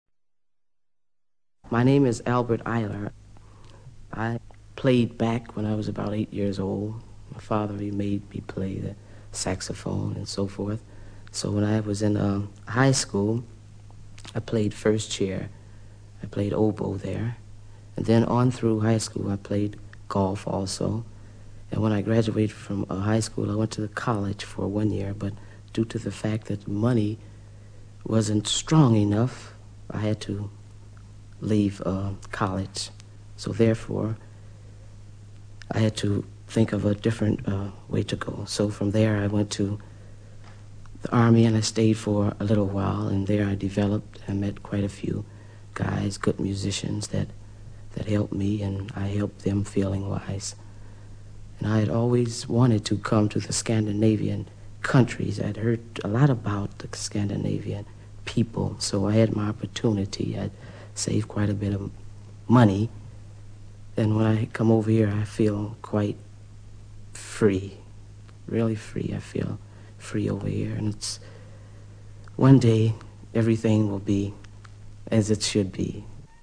Introduction by Albert Ayler (1:20)
Studios of Danish National Radio, Copenhagen